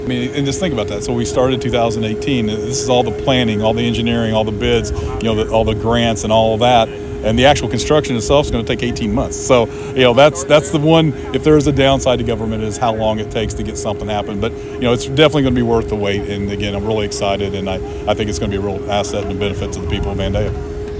After nearly a decade of discussion, planning, designing and more, the construction phase is beginning and Vandalia Mayor Doug Knebel says once the new plant is completed it will be worth the wait.
doug-knebel-for-10-3-25.mp3